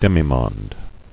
[dem·i·monde]